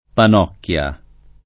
pannocchia [ pann 0 kk L a ]